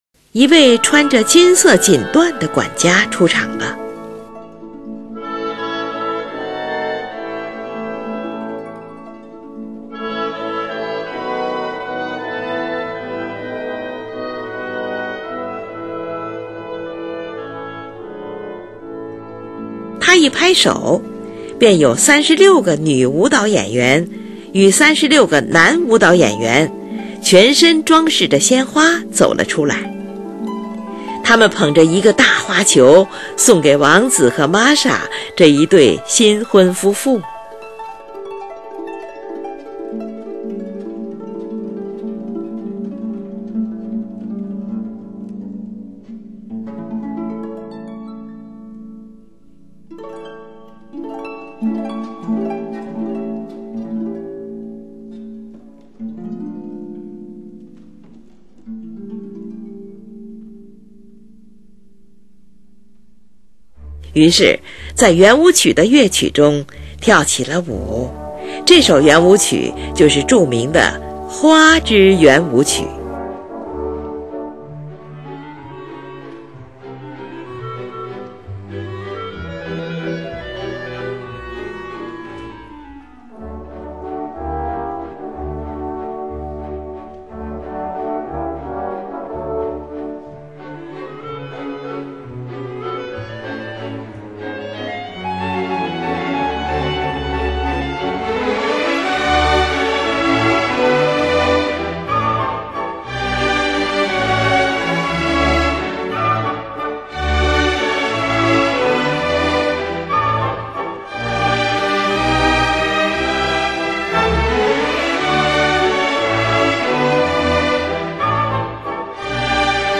高雅清秀，富于诗意，旋律如歌，配器辉煌。
乐曲从竖琴的技巧性华彩乐句（引子）开始，然后法国号便奏出圆舞曲的基本主题。